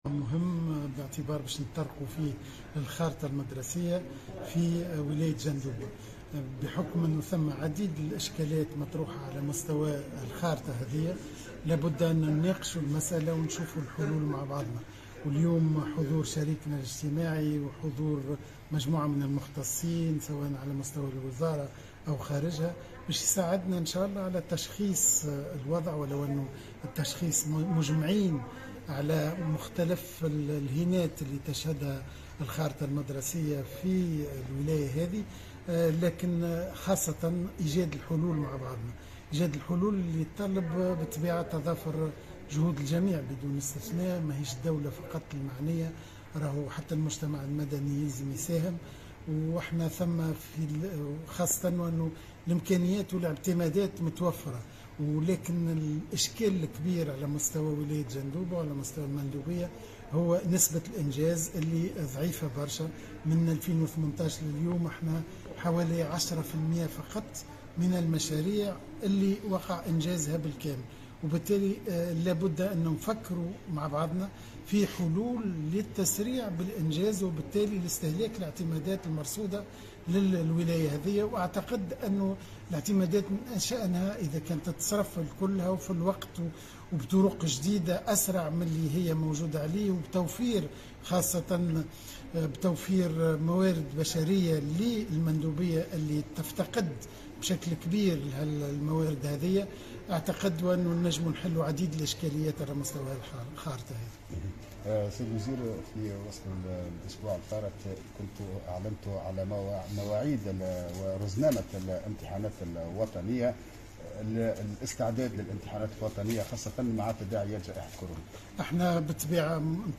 Il a ajouté au correspondant de Tunisie Numérique que le ministère de l’Education est prêt à fournir tous les moyens logistiques, matériels et humains pour garantir le bon déroulement des examens nationaux.